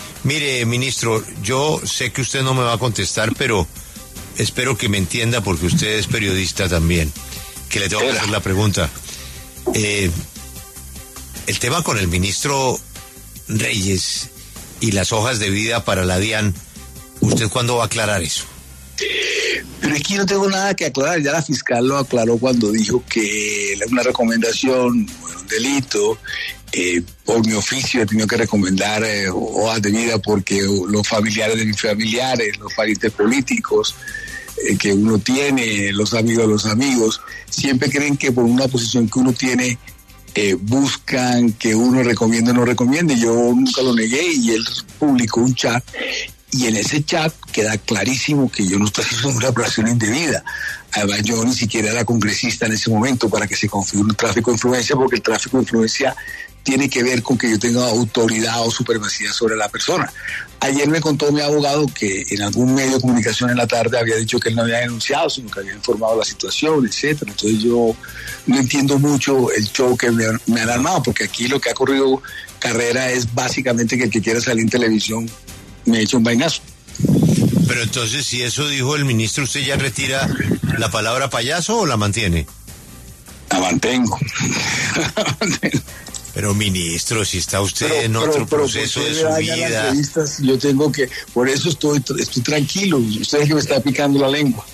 El ministro del Interior, Armando Benedetti, habló en La W sobre su cruce con Luis Carlos Reyes, exministro de Comercio y exdirector de la Dian.